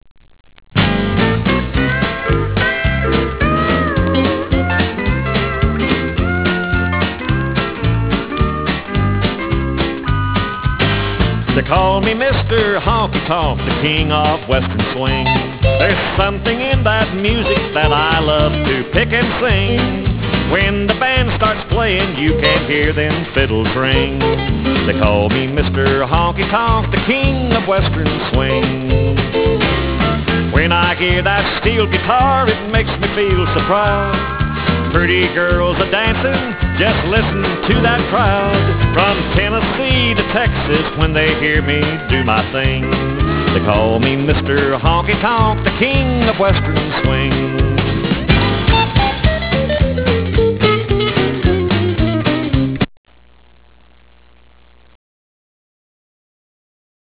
Garage Rock WAV Files